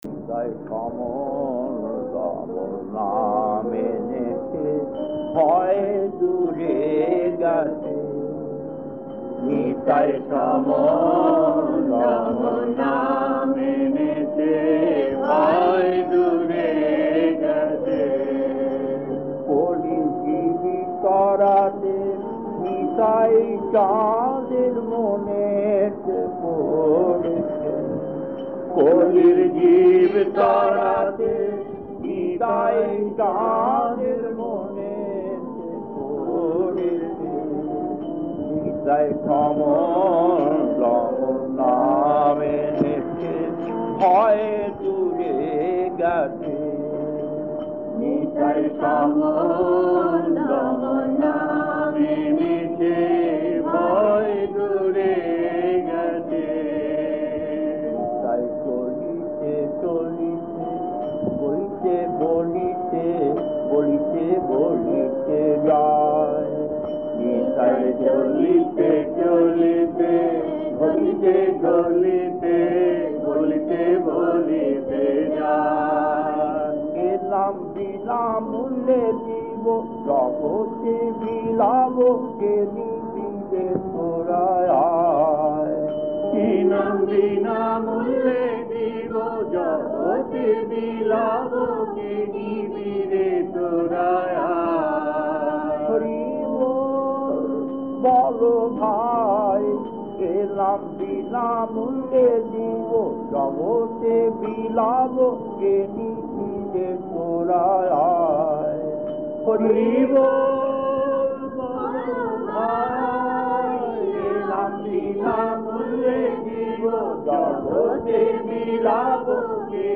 Kirtan D12-1 1.